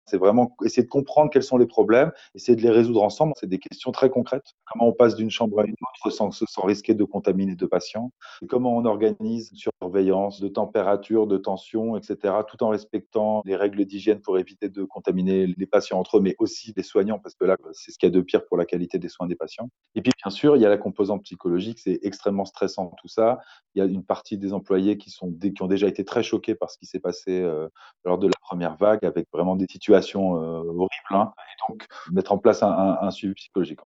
Médecins Sans Frontière lance un recrutement d'urgence pour une mission d'intervention dans les Ehpads en France, en soutien au personnel. Reportage